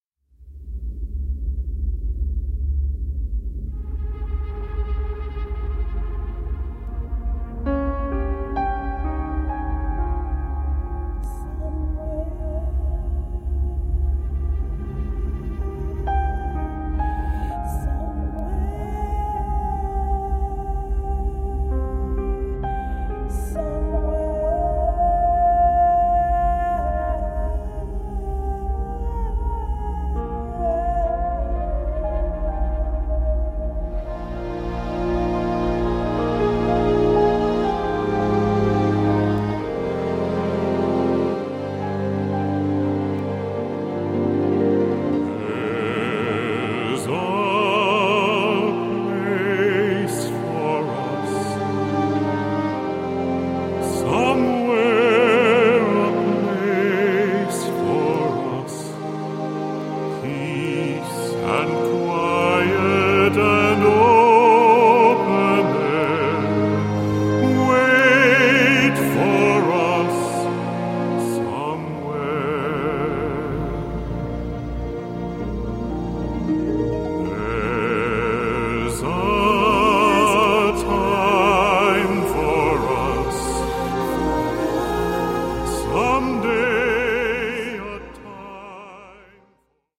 Piano
Pop